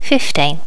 Update all number sounds so they are more natural and remove all clicks.